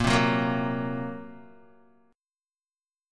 Bbsus2#5 chord